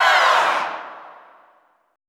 Index of /90_sSampleCDs/Best Service - Extended Classical Choir/Partition I/AHH FALLS
AHH LOWER -R.wav